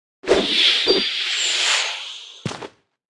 Media:Sfx_Anim_Ultra_Bo.wav 动作音效 anim 在广场点击初级、经典、高手和顶尖形态或者查看其技能时触发动作的音效
Sfx_Anim_Ultra_Bo.wav